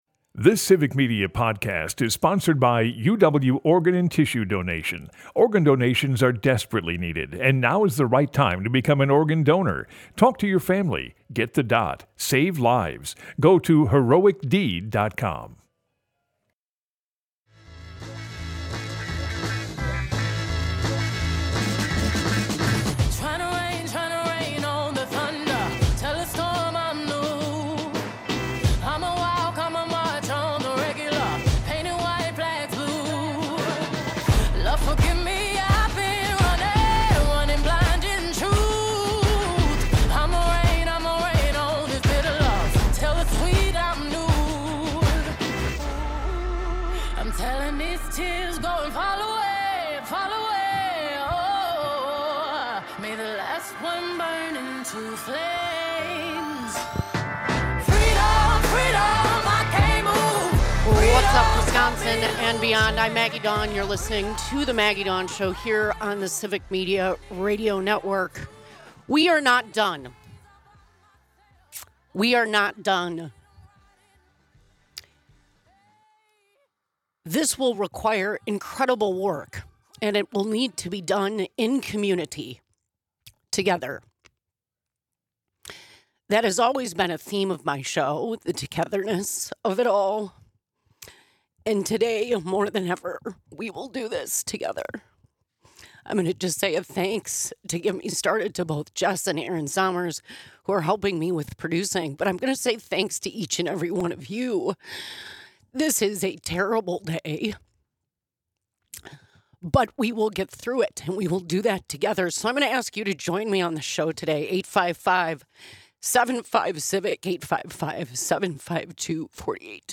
Broadcasts live, 2 - 4 p.m. across Wisconsin.
She takes your phone calls and discusses how things move forward with the results.